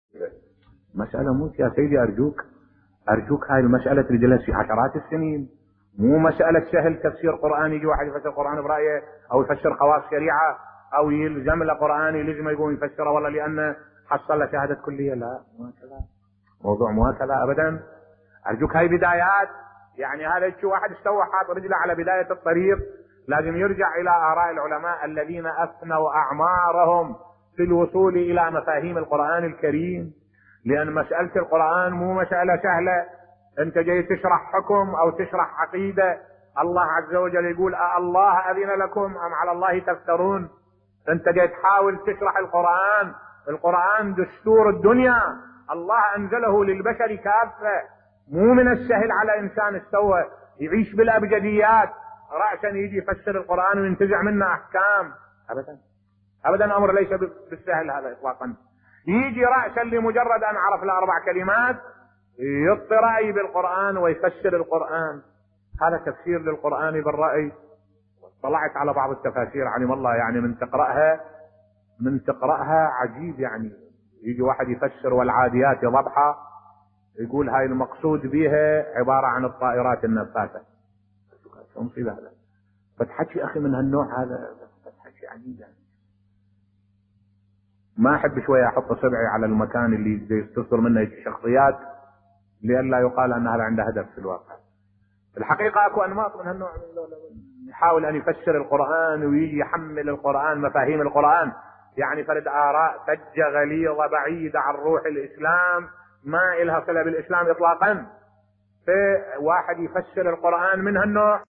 ملف صوتی تهكم من بعض مفسري القرآن الكريم بصوت الشيخ الدكتور أحمد الوائلي